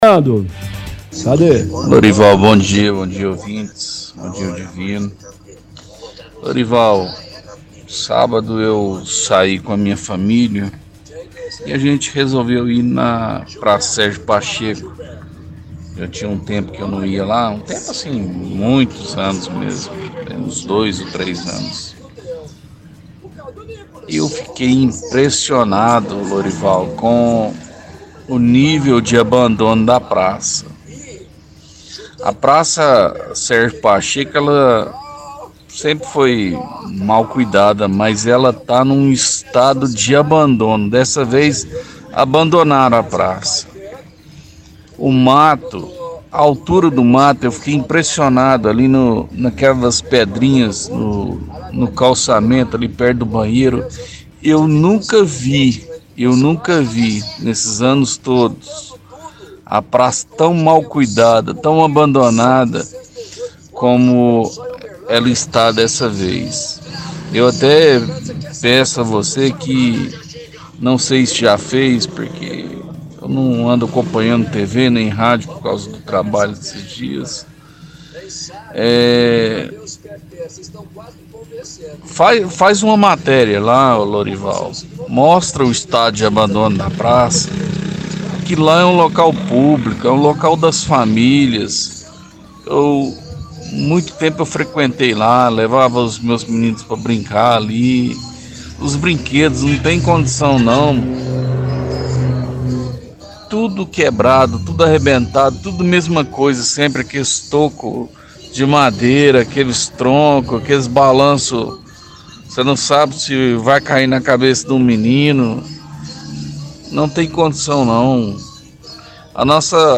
– Ouvinte reclama que Praça Sérgio Pacheco está abandona. Cita que os matos estão altos, brinquedos quebrados.